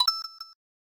Sound effect from Super Mario World 2: Yoshi's Island